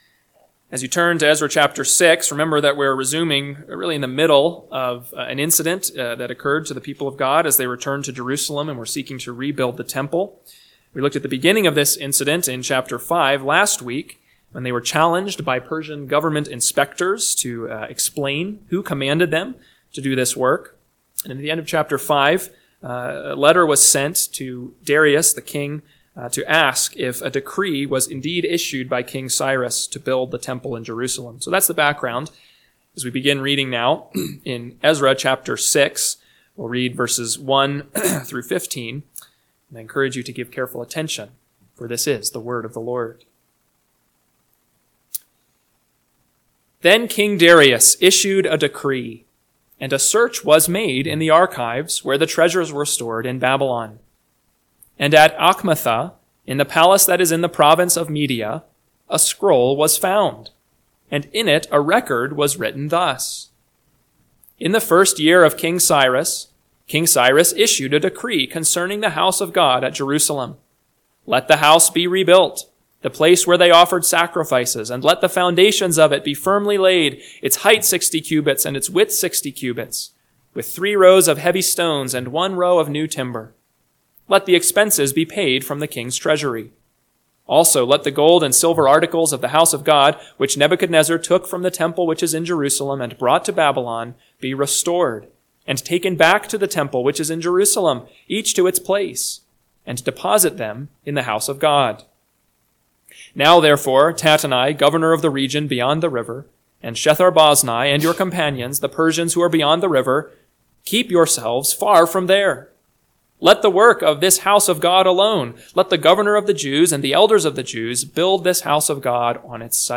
PM Sermon – 4/13/2025 – Ezra 6:1-15 – Northwoods Sermons